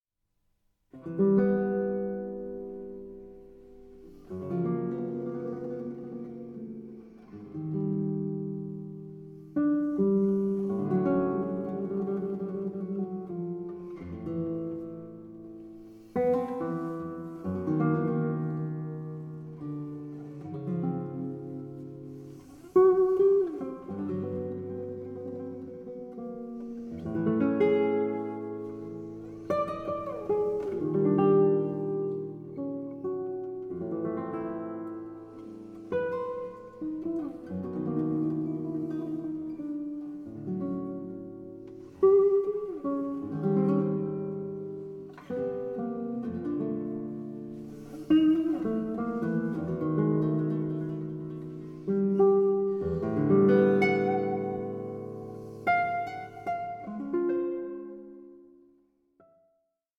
SOLO GUITAR MASTERPIECE
Guitarist